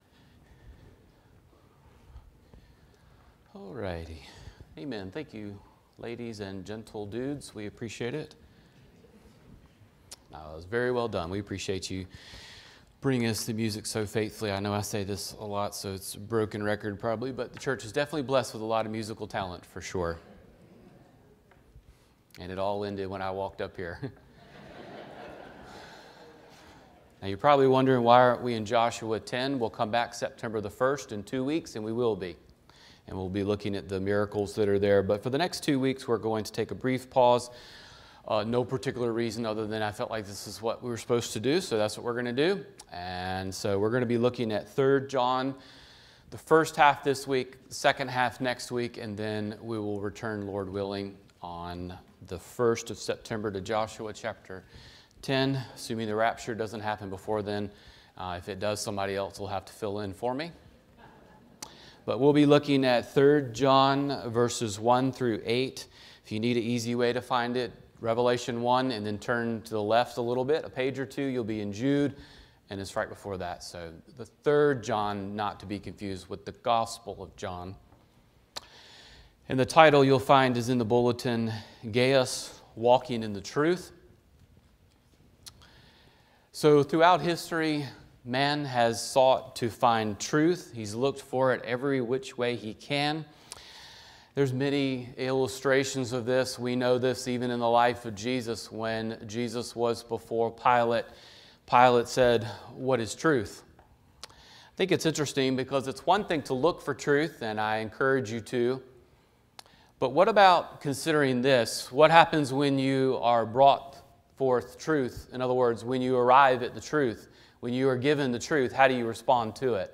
Sermons | Decatur Bible Church